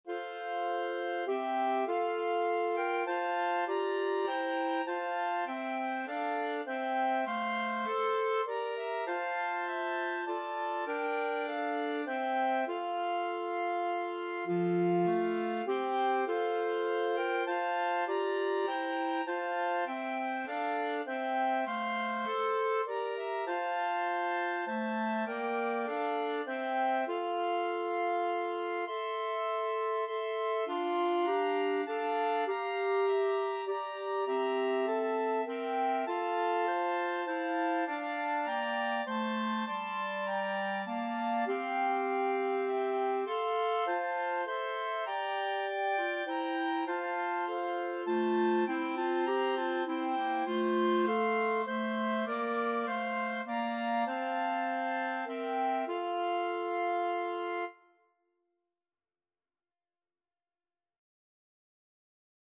Clarinet 1Clarinet 2Clarinet 3Clarinet 4
3/4 (View more 3/4 Music)
Classical (View more Classical Clarinet Quartet Music)